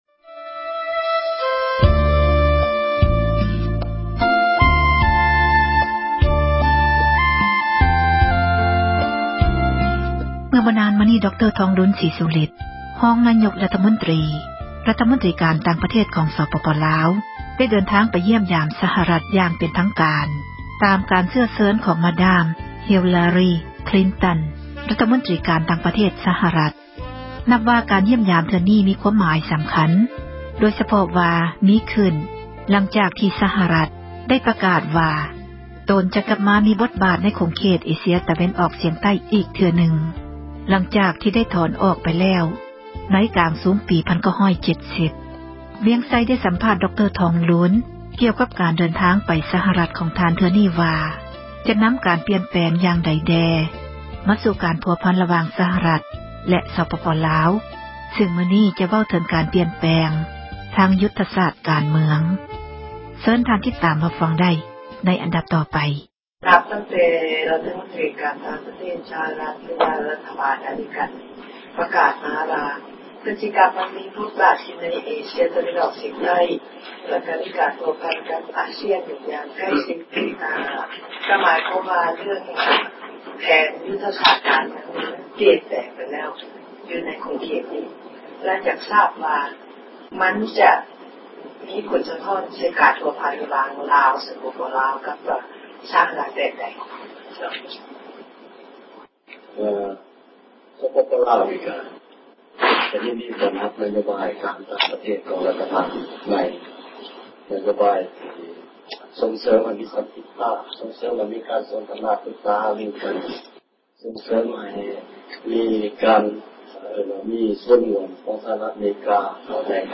ສັມພາດທ່ານທອງລຸນ ການຢ້ຽມຢາມສະຫະຣັດ